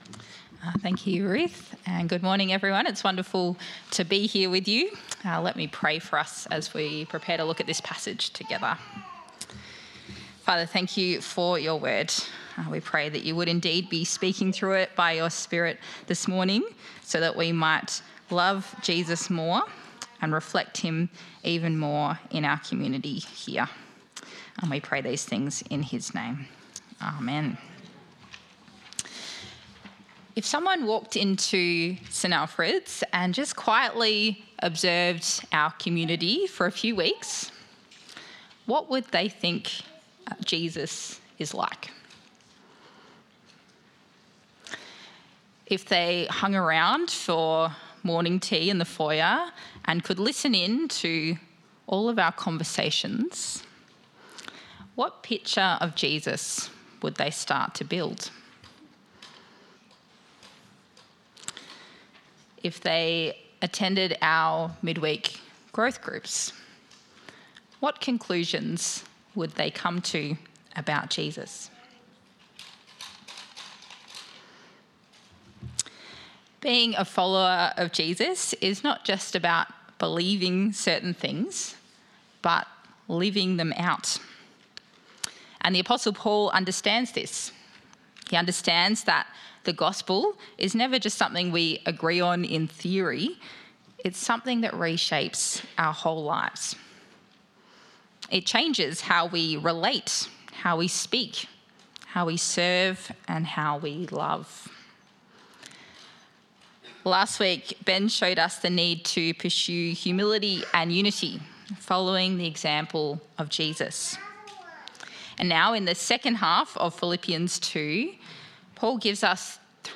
The Bible reading is Philippians 2:12-30.